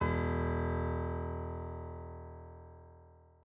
SoftPiano
e0.mp3